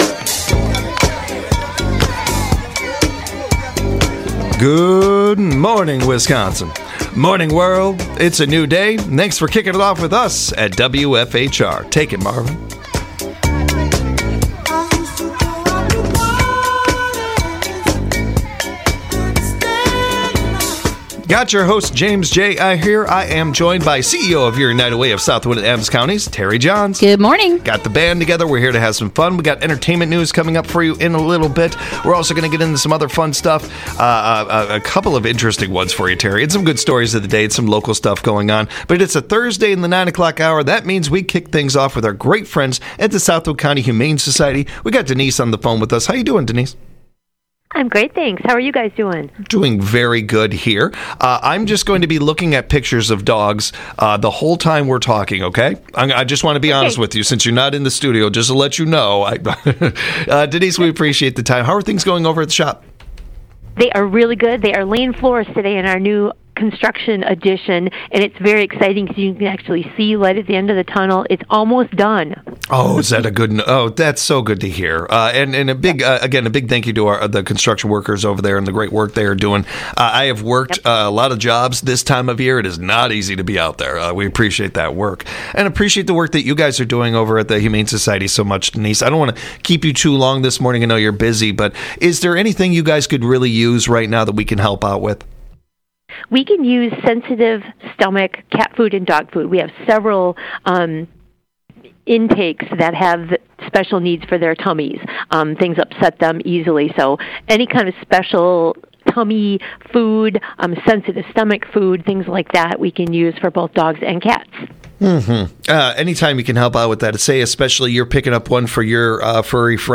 a rotation of entertaining co-hosts